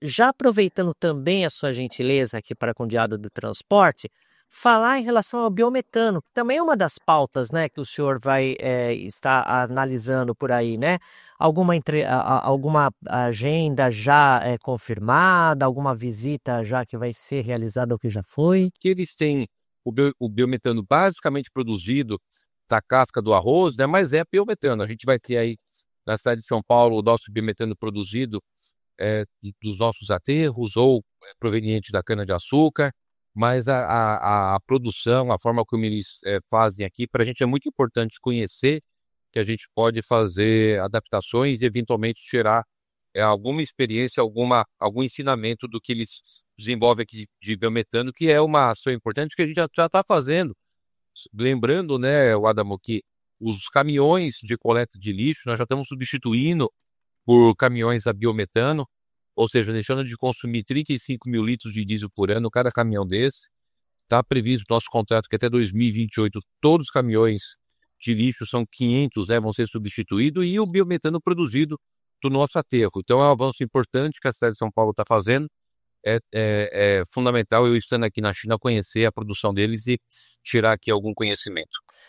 OUÇA O TRECHO QUE NUNES FALOU DO BIOMETANO NA ÁSIA AO DIÁRIO DO TRANSPORTE: